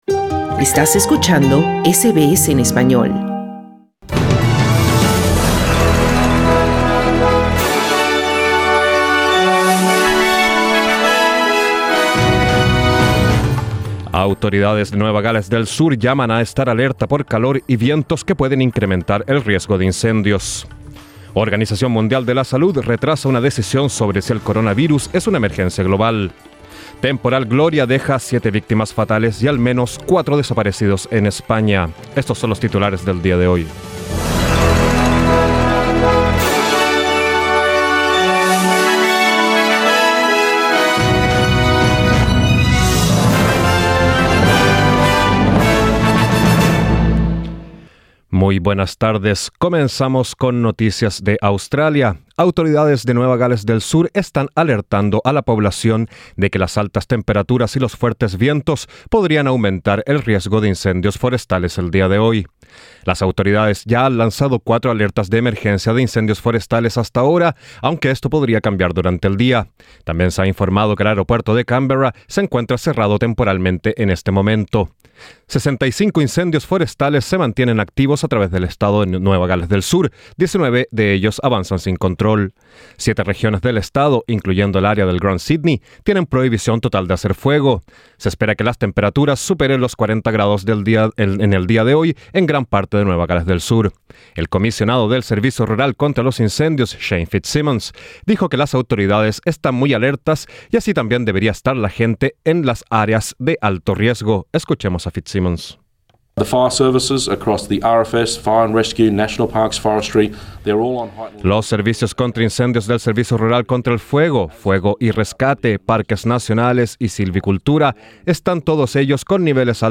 Noticias SBS Spanish | 23 enero 2020